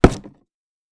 Punching Box Powerful B.wav